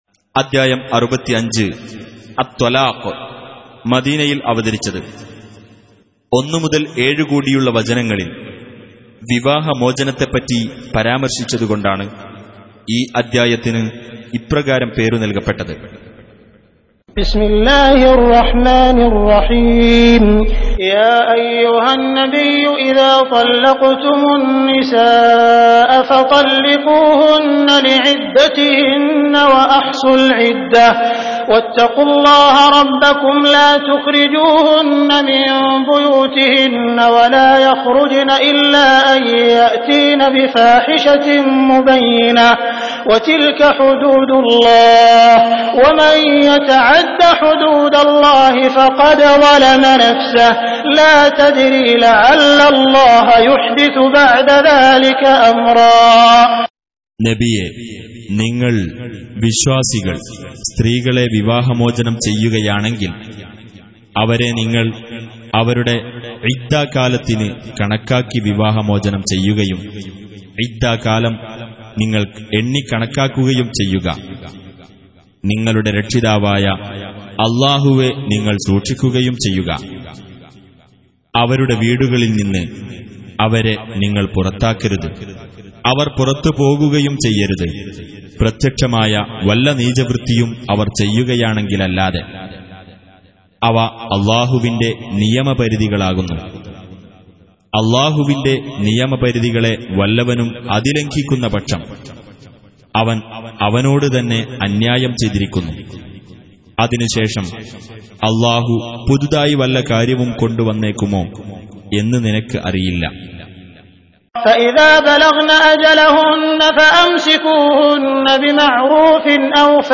Surah Repeating تكرار السورة Download Surah حمّل السورة Reciting Mutarjamah Translation Audio for 65. Surah At-Tal�q سورة الطلاق N.B *Surah Includes Al-Basmalah Reciters Sequents تتابع التلاوات Reciters Repeats تكرار التلاوات